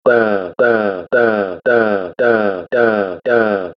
Full ba-da continuum (from Menn, 2011)
Stepwise ba-da continuum, with equal changes between steps